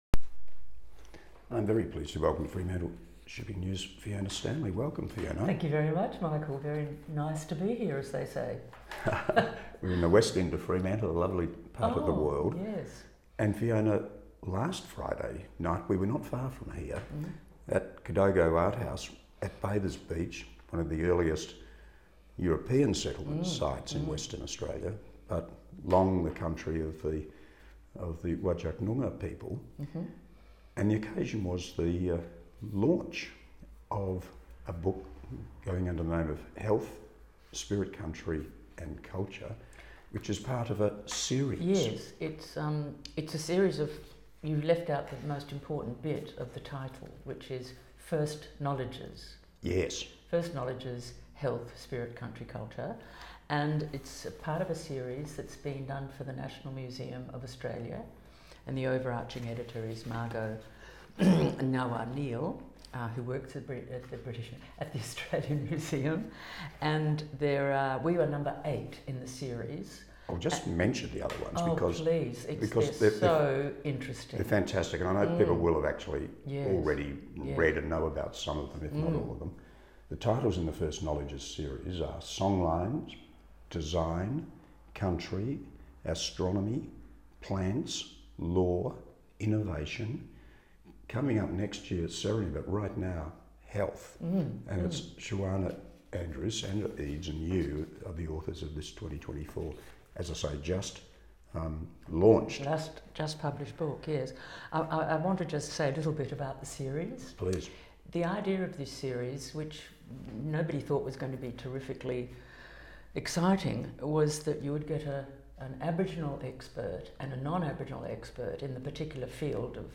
First Knowledges: HEALTH Spirit, Country & Culture – Interview with Fiona Stanley